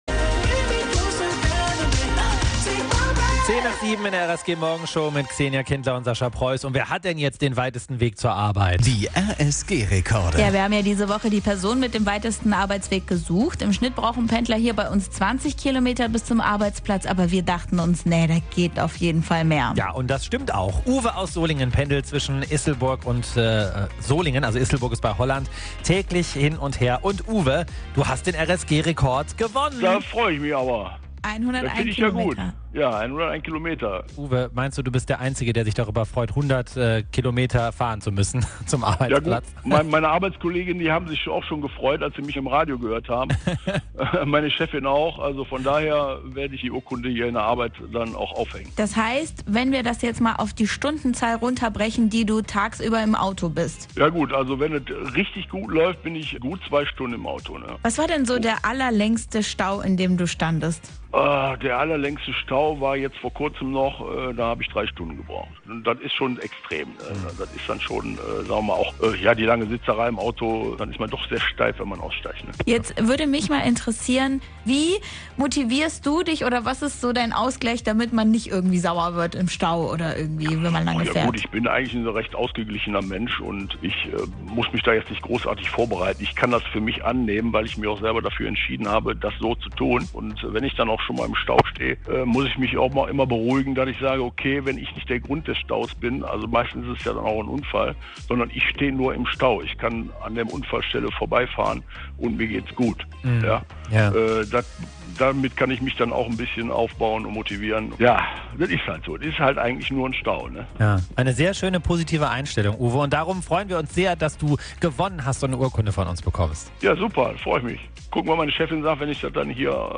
Telefoninterview